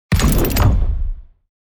Shooter, Fps, Game Menu, Ui Unlock Item Sound Effect Download | Gfx Sounds
Shooter-fps-game-menu-ui-unlock-item.mp3